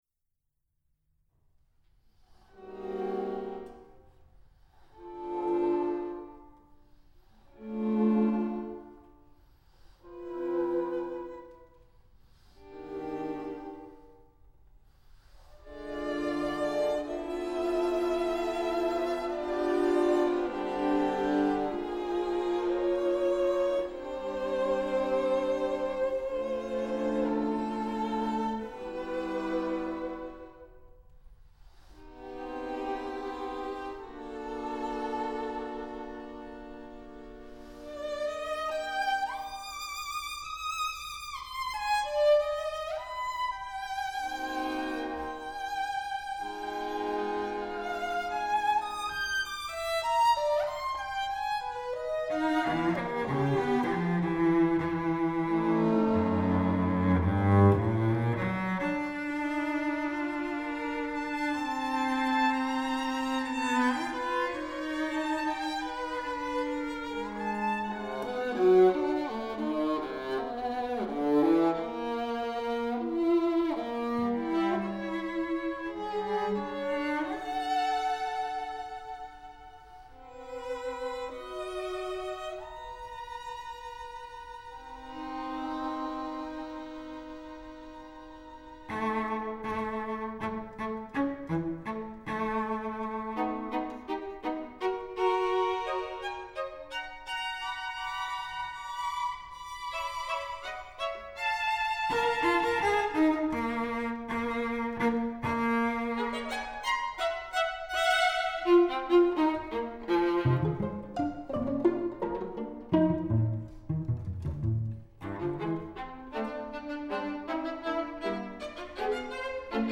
nhạc hòa tấu
cho nhóm tứ tấu đàn dây
violin I
viola
violoncello